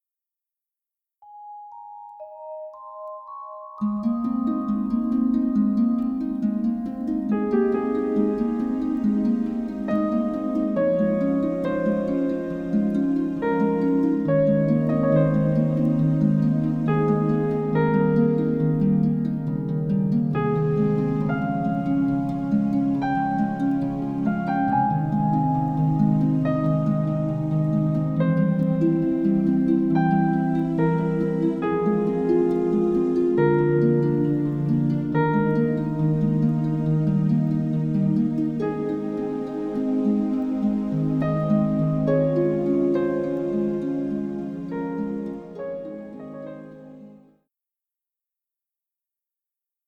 sensitive and gentle score
gentle transparency and full orchestra-like ambience